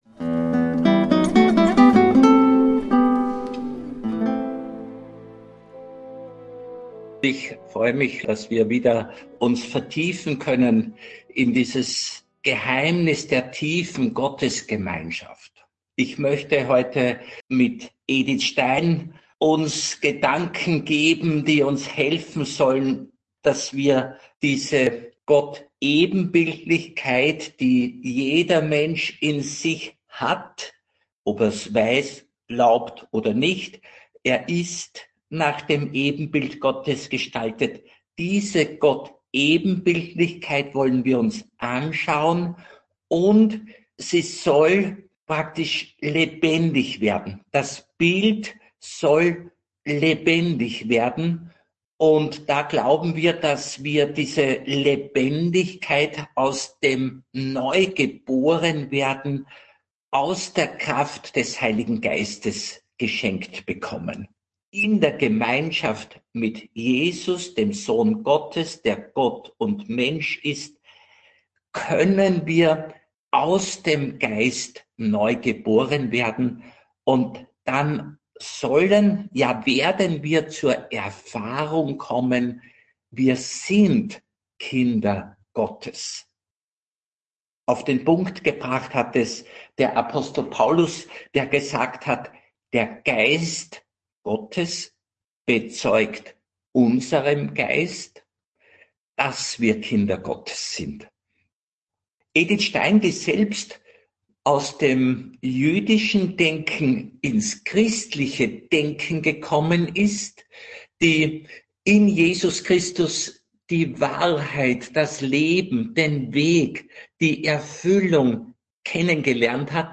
(Radio Maria Aufzeichnung vom 18.6.2025) Mehr